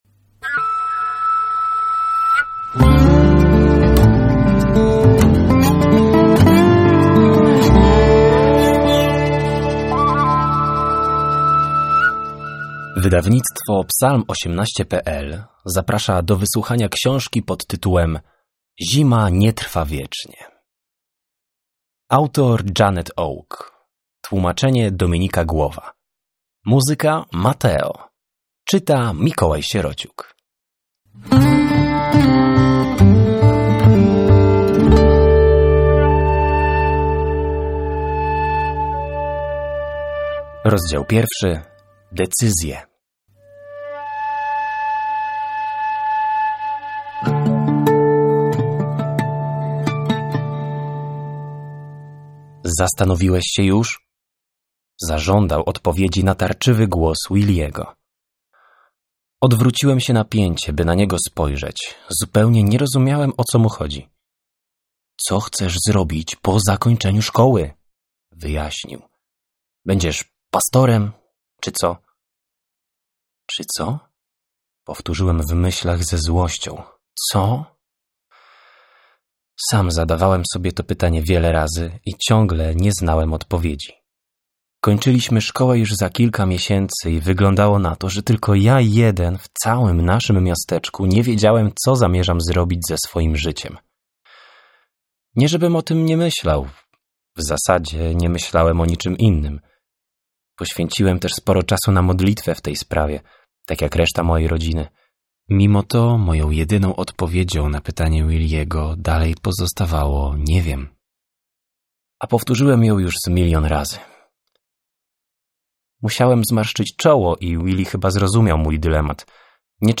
Zima nie trwa wiecznie – Audiobook
PROBKA-Zima-nie-trwa-wiecznie-audiobook.mp3